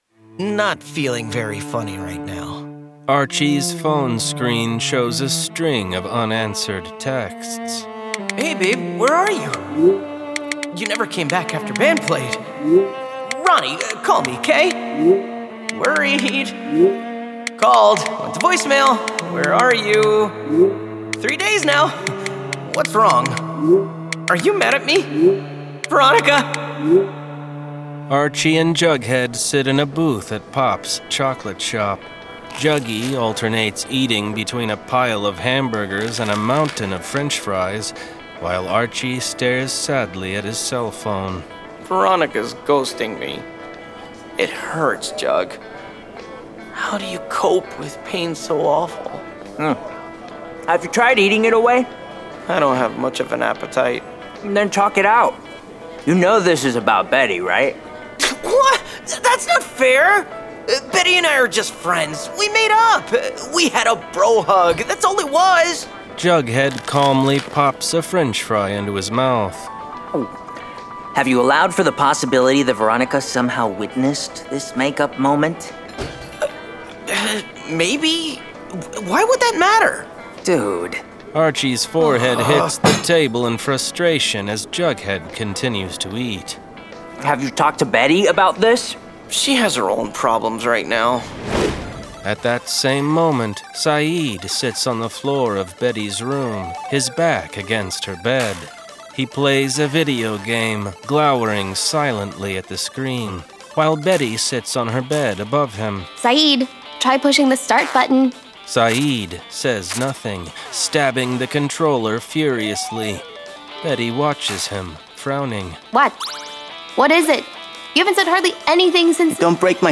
Full Cast. Cinematic Music. Sound Effects.
[Dramatized Adaptation]
Adapted from Archie issues and produced with a full cast of actors, immersive sound effects and cinematic music.